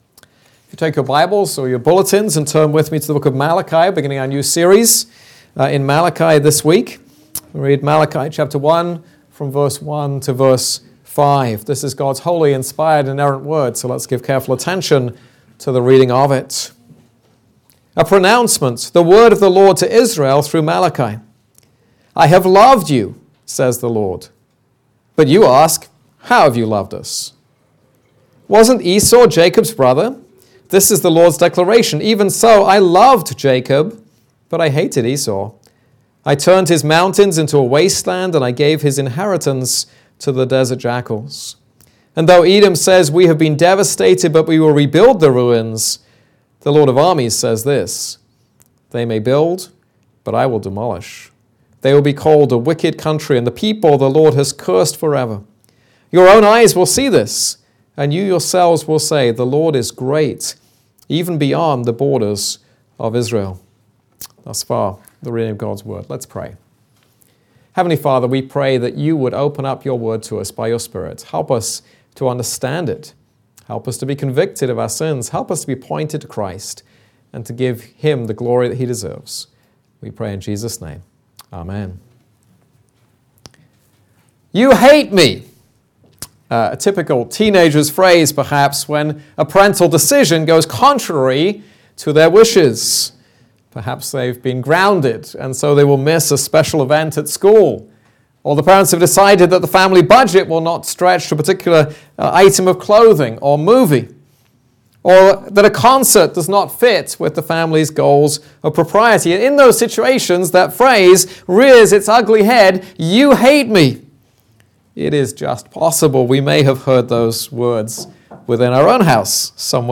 This is a sermon on Malachi 1:1-5.